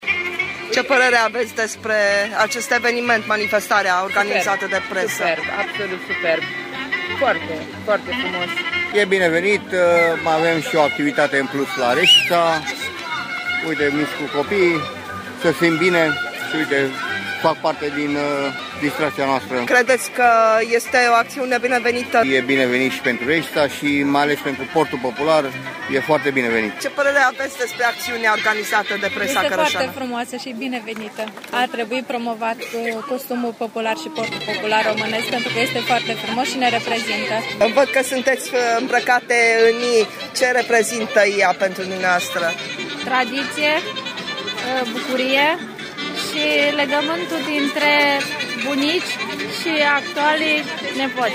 a cules câteva impresii de la participanţi: